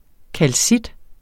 Udtale [ kalˈsid ]